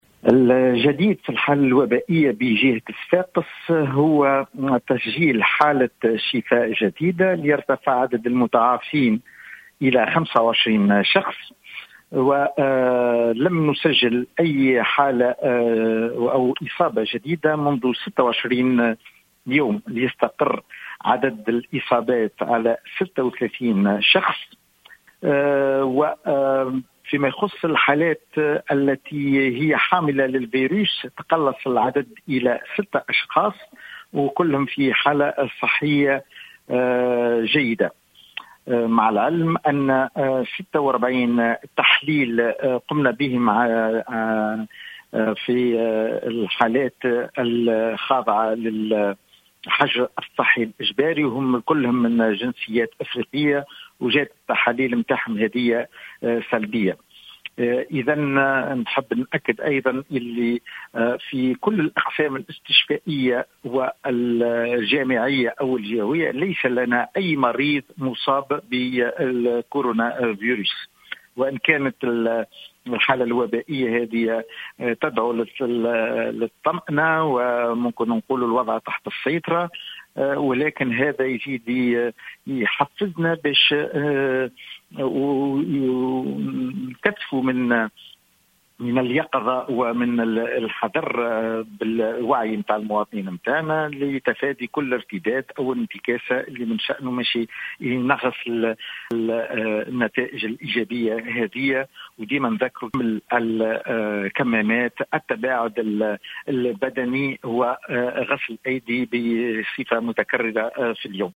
أكد المدير الجهوي للصحة بصفاقس، علي العيادي في تصريح اليوم لـ"الجوهرة أف أم" عدم تسجيل أي إصابات جديدة بفيروس "كورونا" منذ 26 يوما.